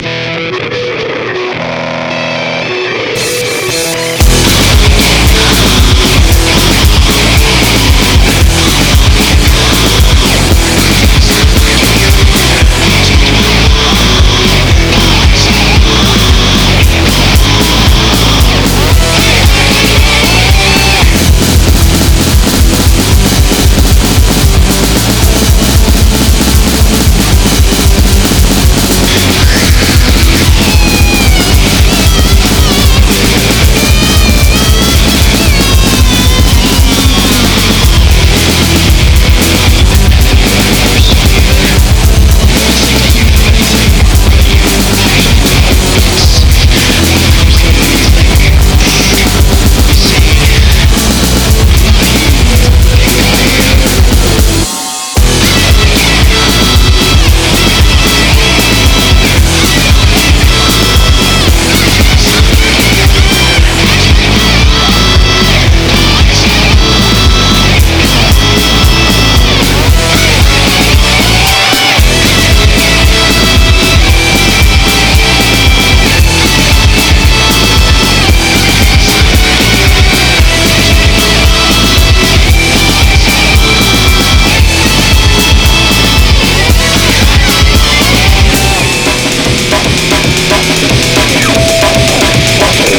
punk, metal, hardcore, grindcore, noise, ,